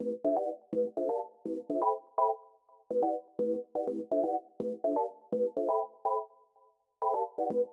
描述：这是我为我最喜欢的声音之一制作的补丁，当火开始燃烧的时候，披露的声音。
Tag: 124 bpm Garage Loops Synth Loops 1.30 MB wav Key : Unknown